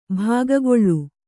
♪ bhāgangoḷḷu